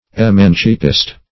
\E*man"ci*pist\